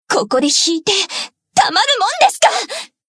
贡献 ） 分类:蔚蓝档案语音 协议:Copyright 您不可以覆盖此文件。
BA_V_Aru_Newyear_Battle_Damage_3.ogg